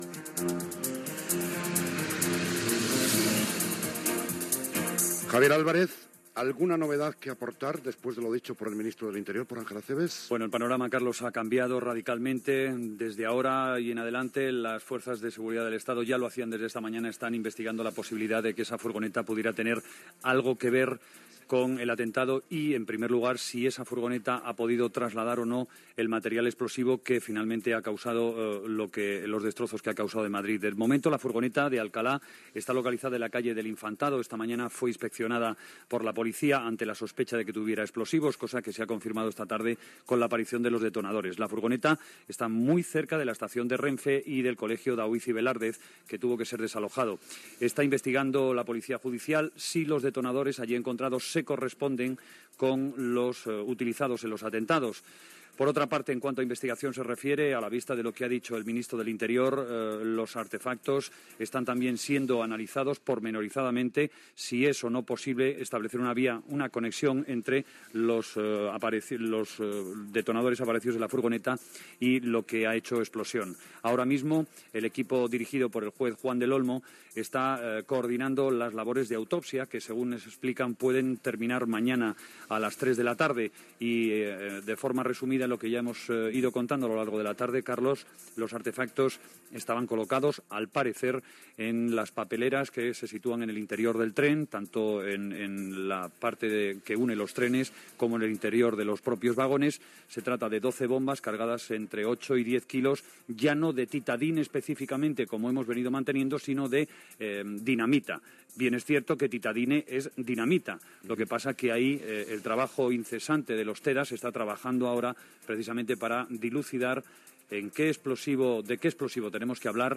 Connexió amb el dipòsit de cadàvers en un pavelló d'IFEMA de Madrid.
Entrevista informativa. Informació de la represa parcial del servei de trens a Atocha a l'endemà.